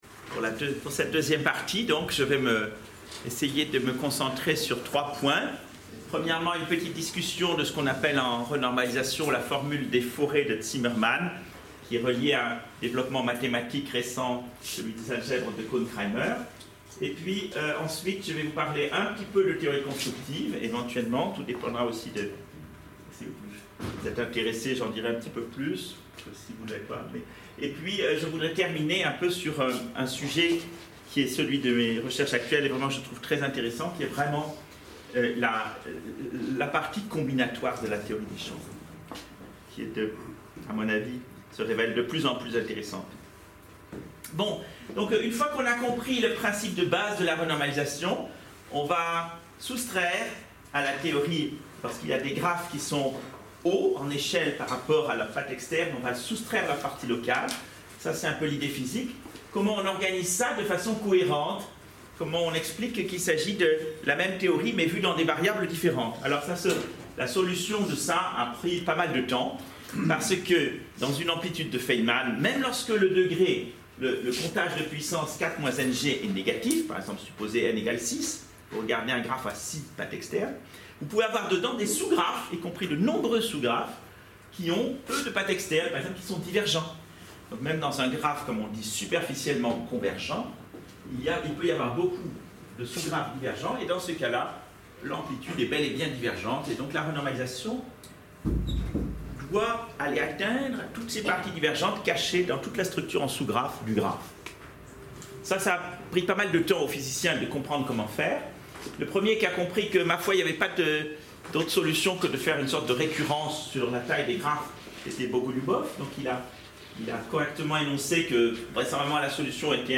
Au cours de cette lecon on expliquera cette formule et en quoi pour démontrer qu'elle résoud le problème, il faut l'organiser, sous une forme ou une autre, à l'aide d'une analyse multi-échelles menant au point de vue moderne dit du groupe de renormalisation de Wilson.